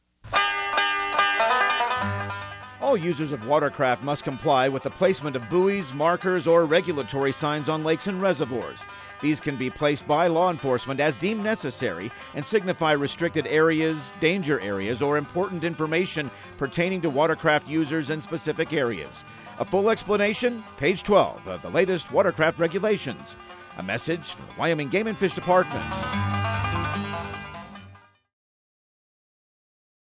Radio news | Week of May 26
Outdoor Tip/PSA